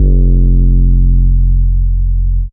808s
bless yo 808.wav